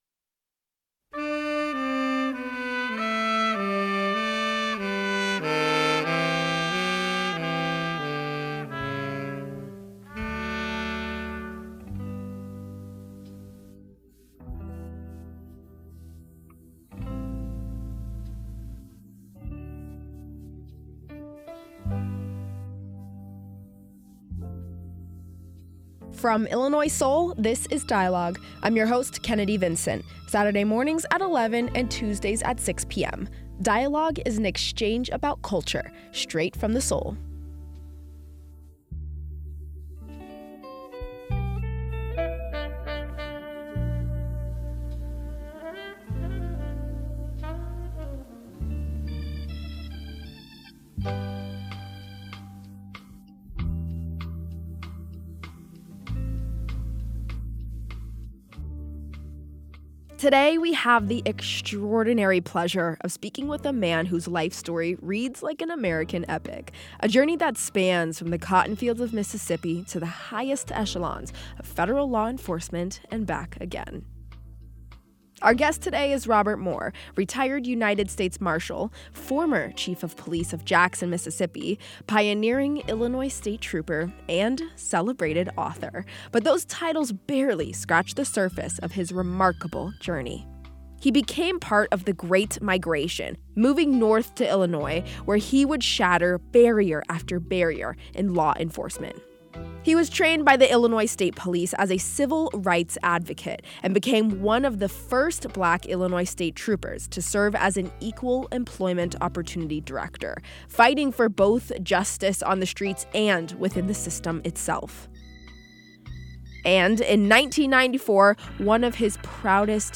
__ GUESTS: Robert Moore Retired United States Marshal, former Chief of Police of Jackson, Mississippi, pioneering Illinois State Trooper, and celebrated author.